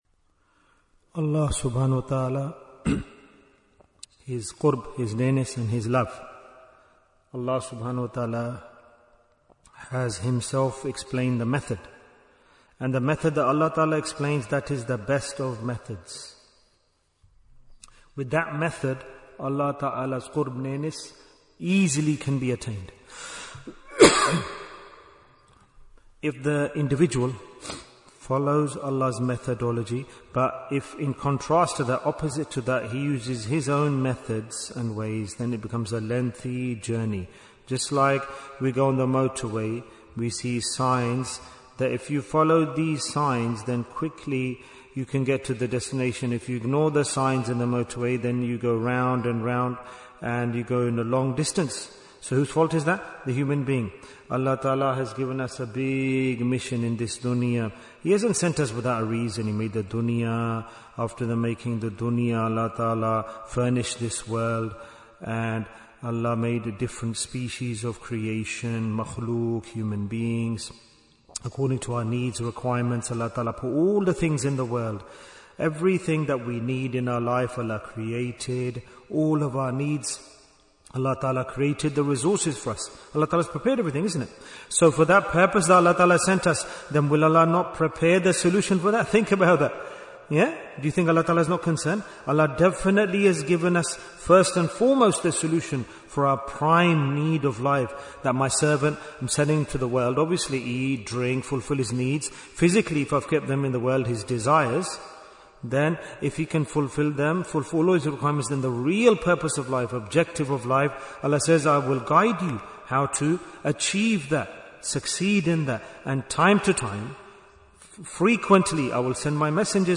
Why is Tazkiyyah Important? - Part 4 Bayan, 26 minutes14th January, 2026